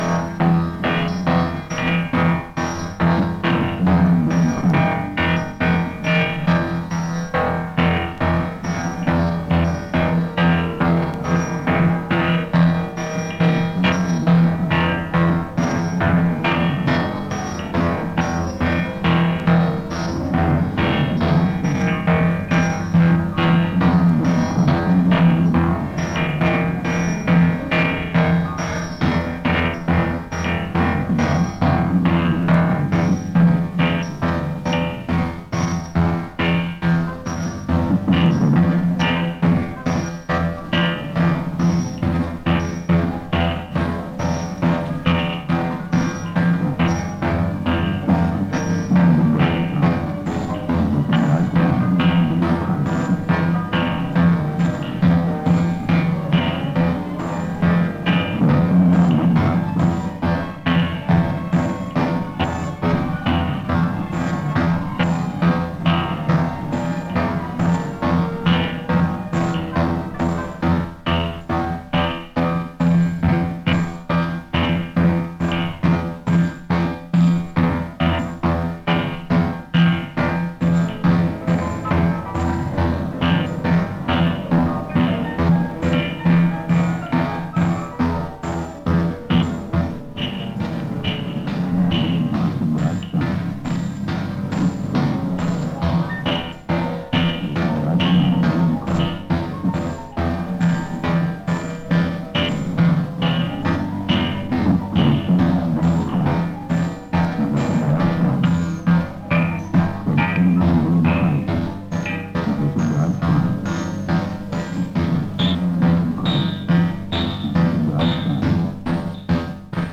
Música bélica.